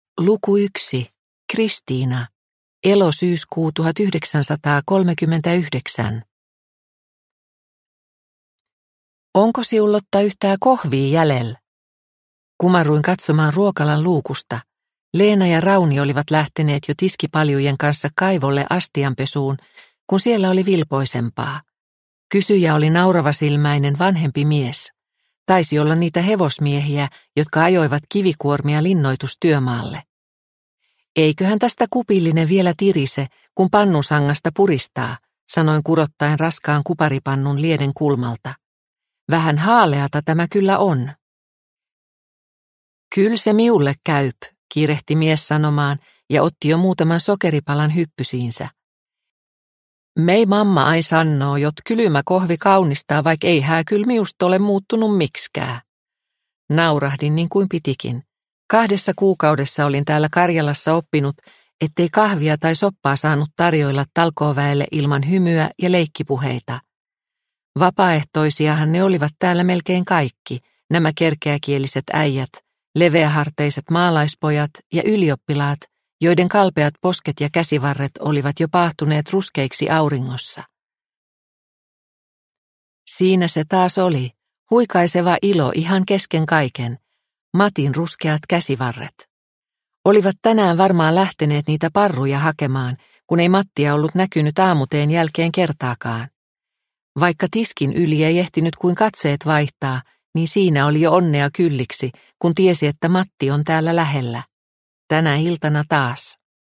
Kielon jäähyväiset – Ljudbok – Laddas ner